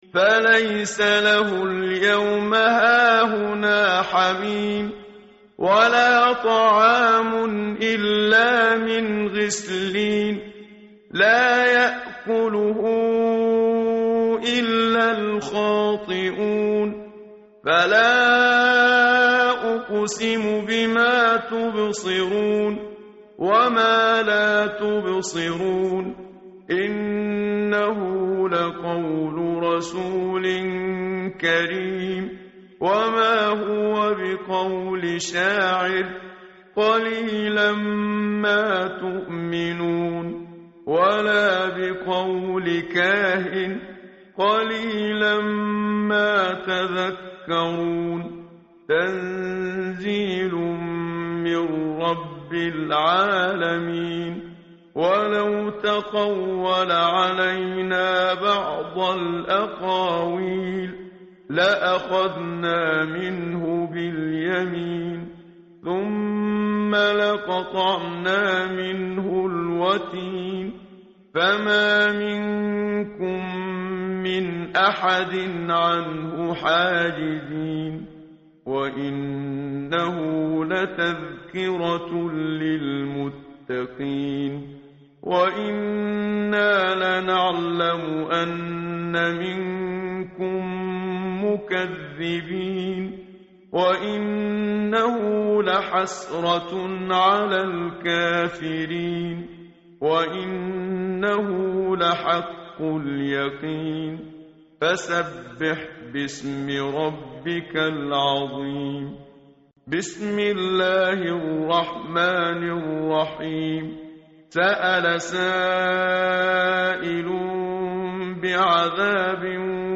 tartil_menshavi_page_568.mp3